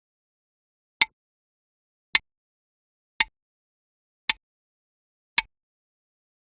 zz-un-floor-goban.v5.webm